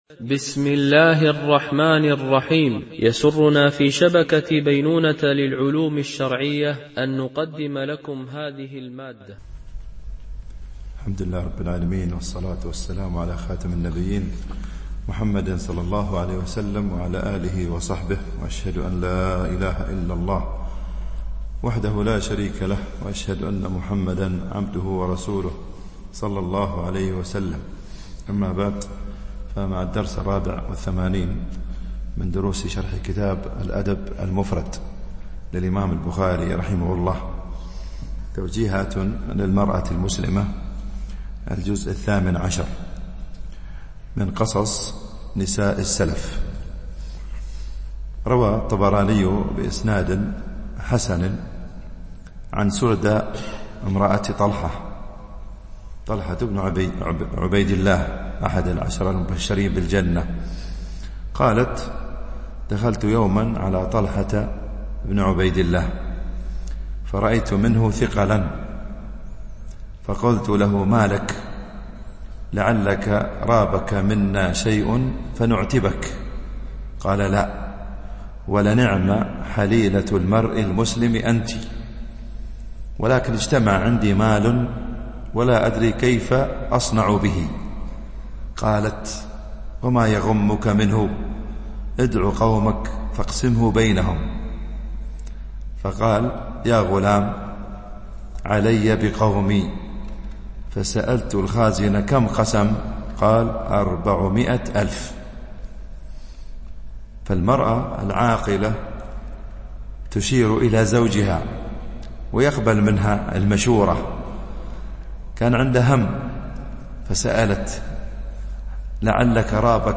شرح الأدب المفرد للبخاري ـ الدرس 84 ( الحديث 609 -612)